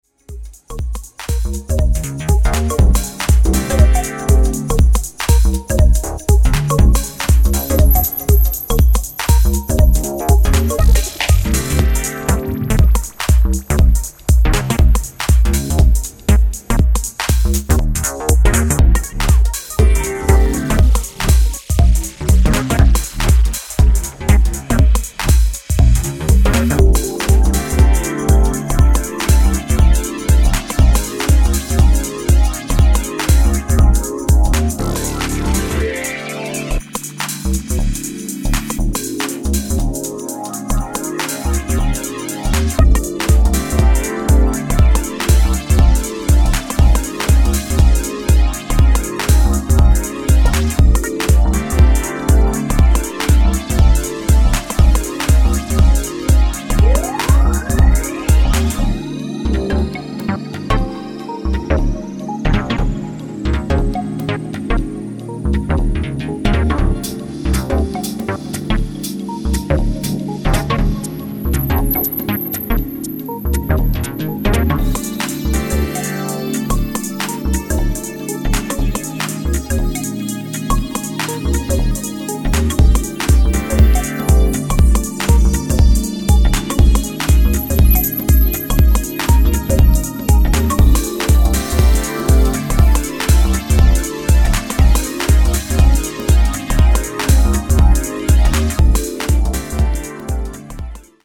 耳障りの良いシンセにハマれる秀作です！